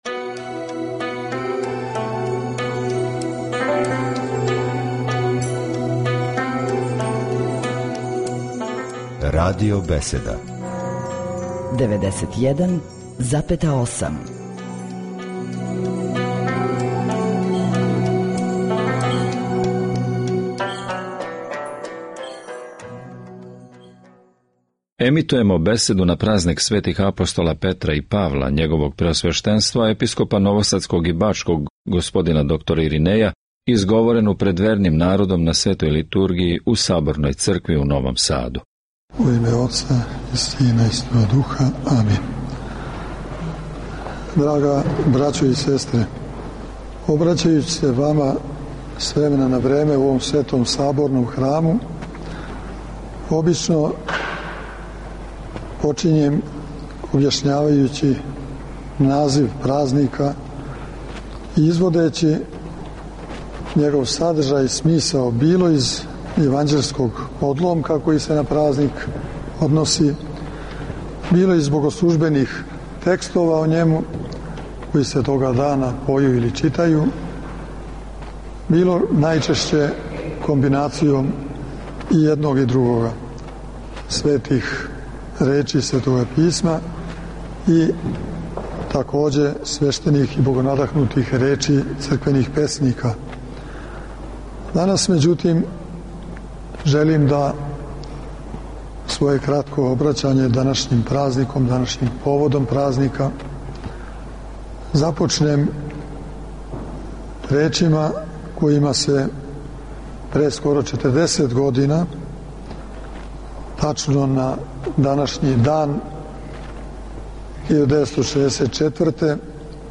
Нови Сад - Празник светих славних и свехвалних првопрестолника и првоврховних апостола Христових Петра и Павла свечано је прослављен широм Епархије бачке, почев од катедралне цркве у Новом Саду, где је литургијско славље предводио Његово Преосвештенство Епископ бачки Господин др Иринеј, уз саслужење два презвитера и шест ђакона.